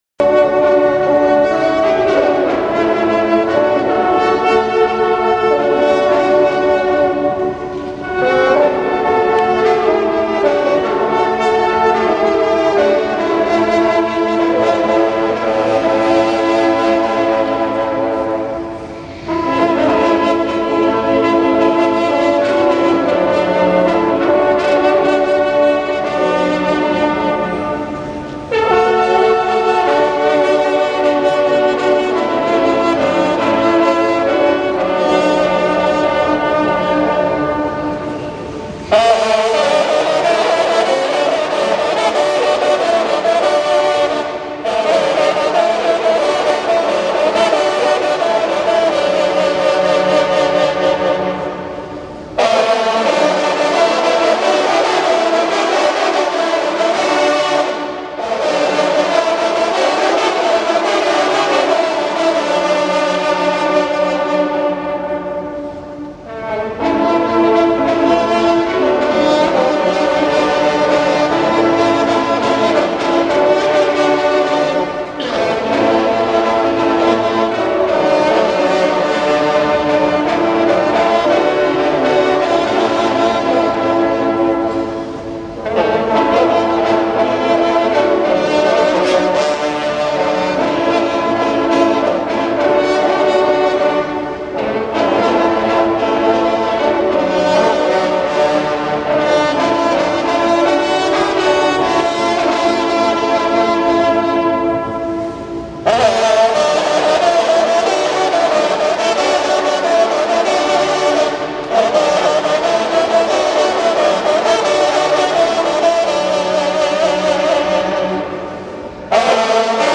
Dimanche 21 novembre 2010 Le Rallye a sonné la messe en la Cathédrale Saint Louis de Versailles, comme chaque année à l'occasion de la célébration du Christ Roi. Quatorze sonneurs, tous membres du Rallye, ont contribué à la célébration qui fait partie intégrante de nos traditions.
le Souvenir de Vincennes, sonné pendant la communion / cliquer sur le nom de la fanfare si vous ne l'entendez pas